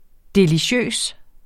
Udtale [ deliˈɕøˀs ]